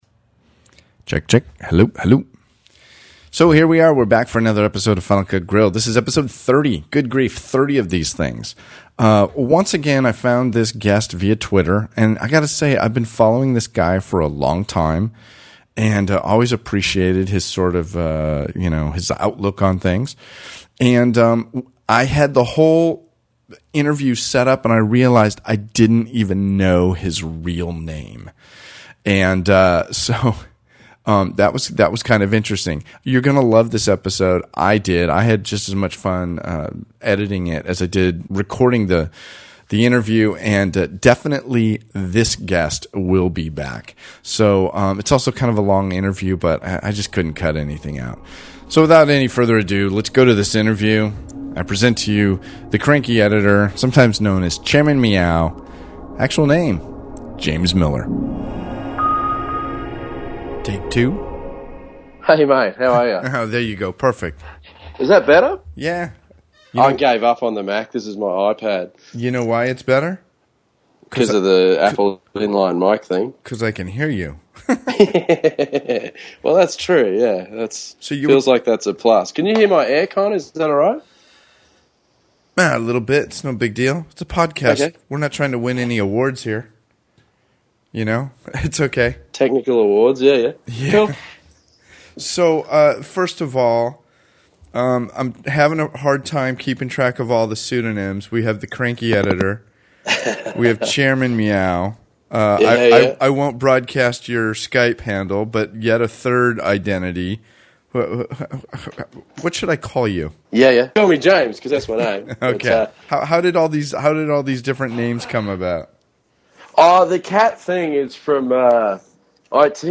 This interview was without a doubt the most fun I’ve ever had on The Grill.